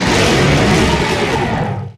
Audio / SE / Cries / KOMMOO.ogg